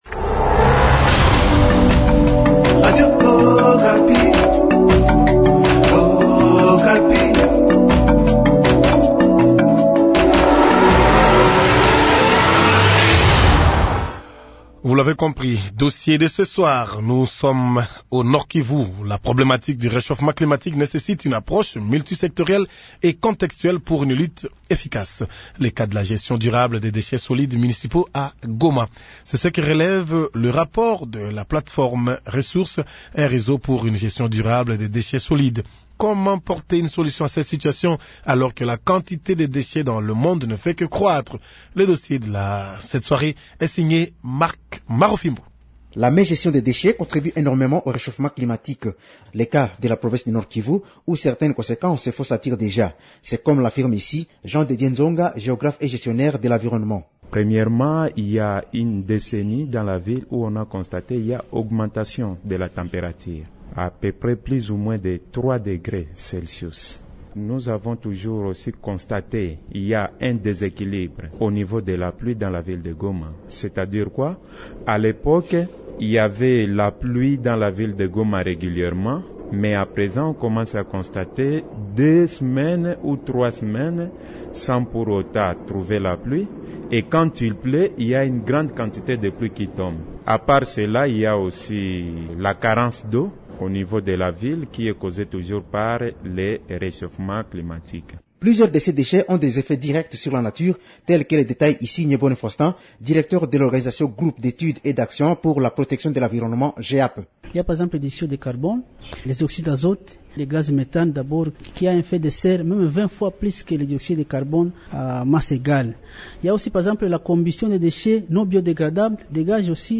Journal Français Soir